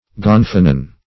Gonfalon \Gon"fa*lon\, Gonfanon \Gon"fa*non\, n. [OE. gonfanoun,